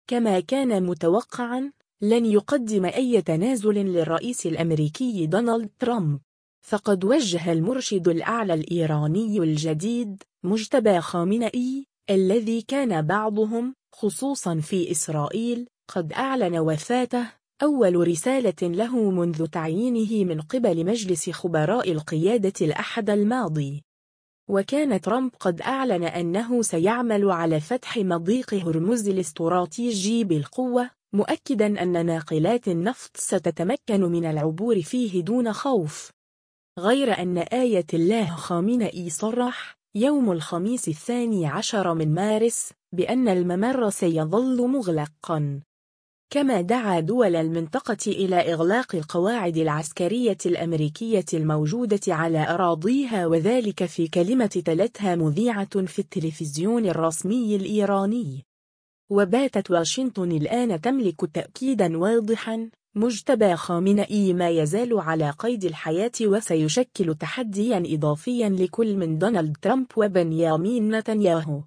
كما دعا دول المنطقة إلى إغلاق القواعد العسكرية الأمريكية الموجودة على أراضيها و ذلك في كلمة تلتها مذيعة في التلفزيون الرسمي الإيراني.